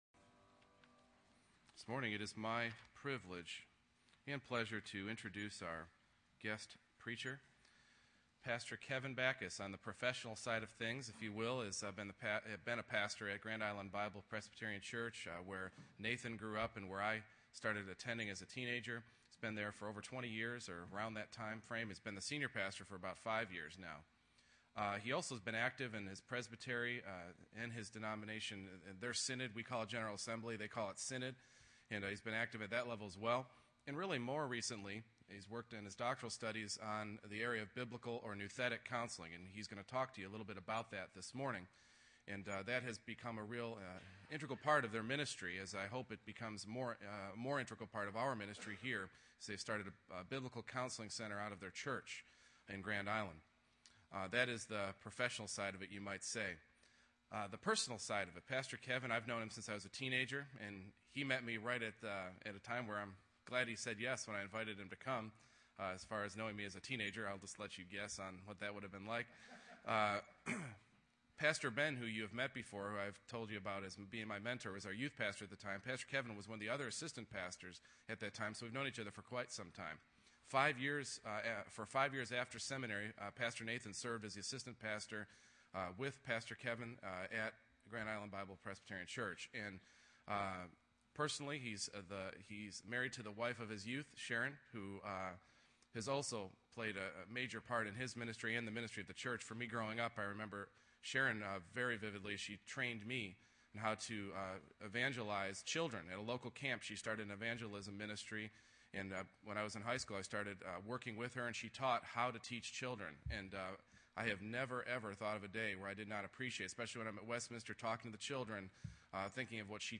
Passage: 2 Peter 1:2-9 Service Type: Morning Worship